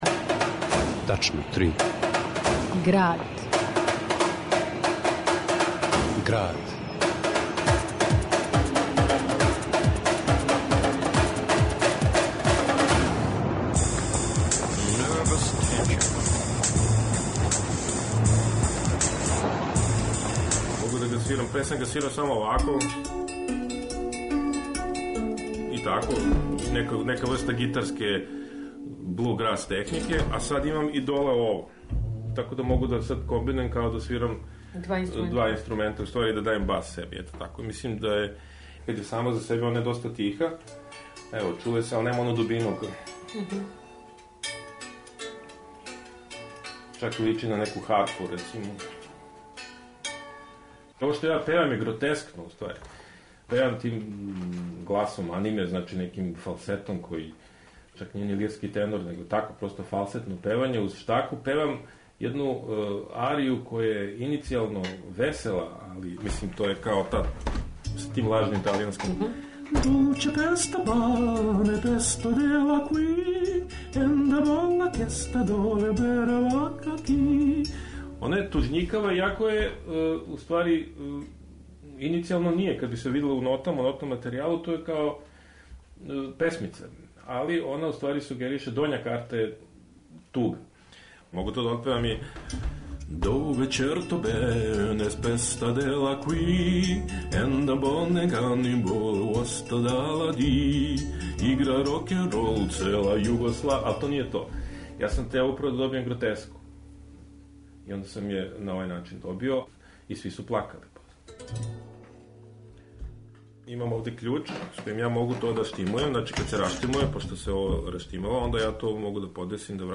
Музика за штакалину, мобилни телефон, мбиру, глас, контрабас, клавир, флауту...